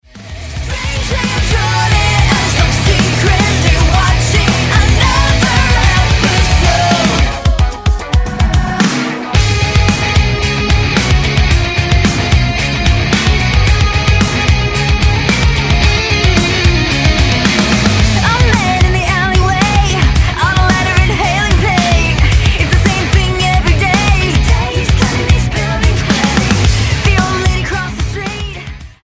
Rock Album
Style: Pop